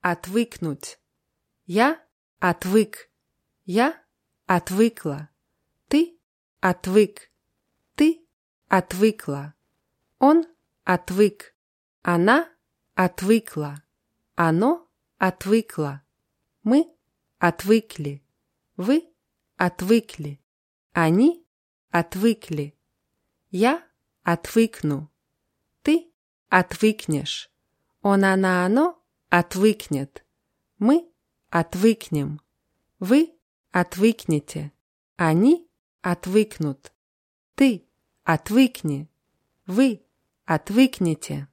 отвыкнуть [atwýknutʲ]